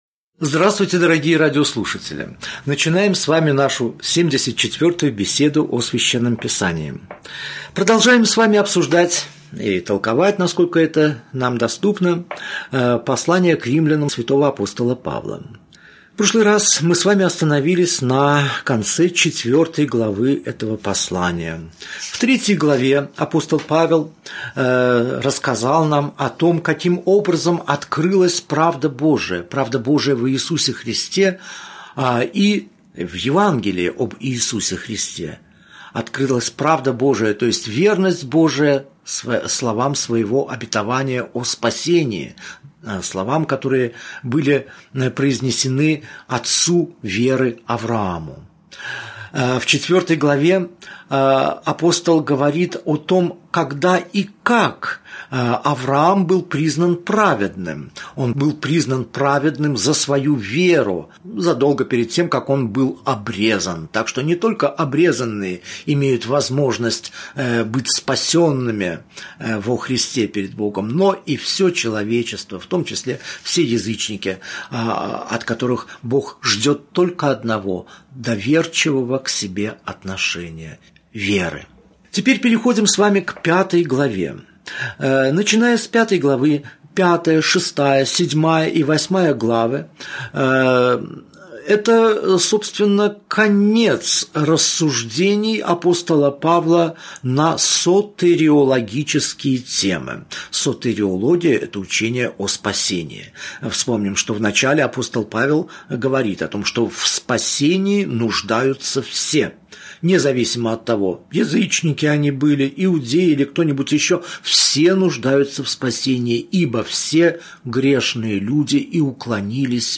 Аудиокнига Беседа 74. Послание к Римлянам. Глава 5 – глава 6 | Библиотека аудиокниг